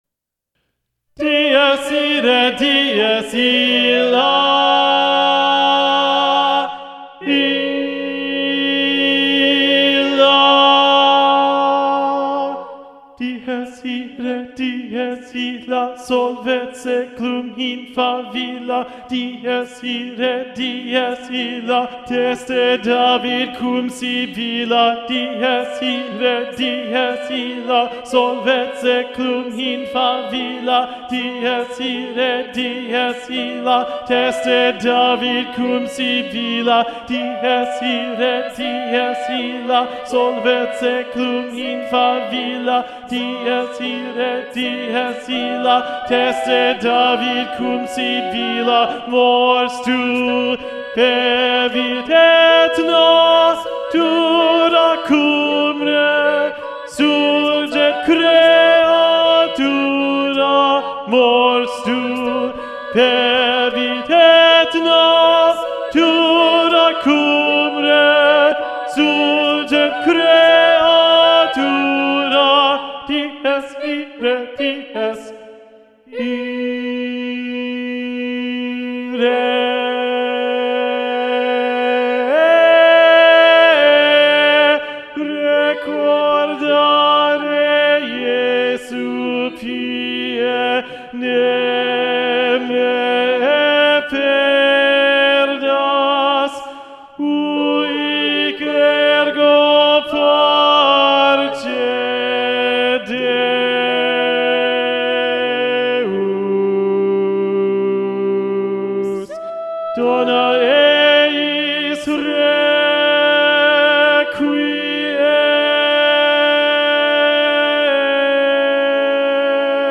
- Œuvre pour chœur à 8 voix mixtes (SSAATTBB)
SATB Tenor 2 Predominant